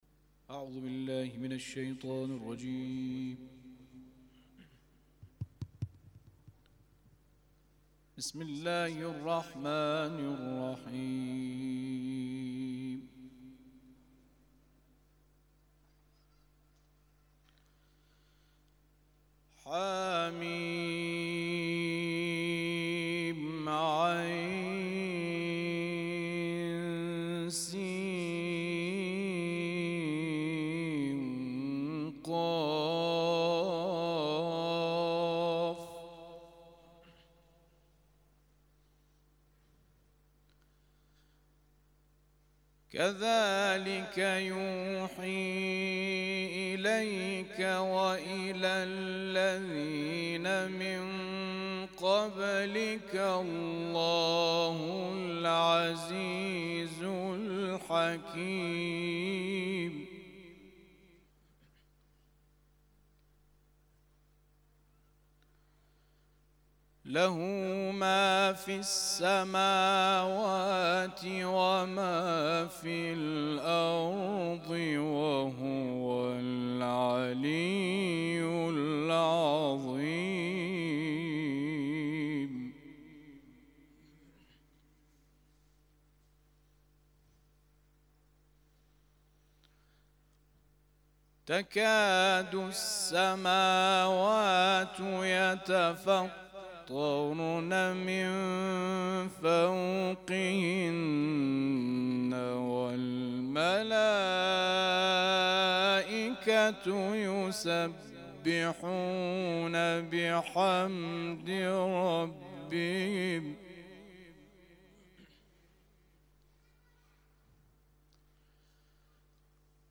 تلاوت ظهر